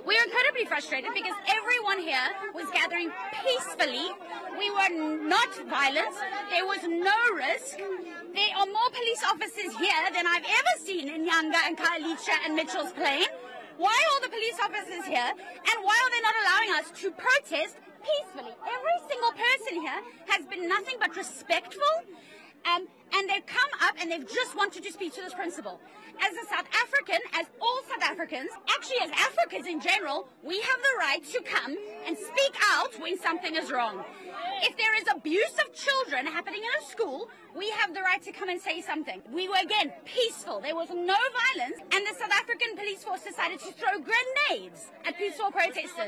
Speaking to our sister channel eNCA, a resident slammed the police’s response, saying officers had no right to use force against peaceful demonstrators.